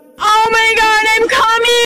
omg im coming Meme Sound Effect
This sound is perfect for adding humor, surprise, or dramatic timing to your content.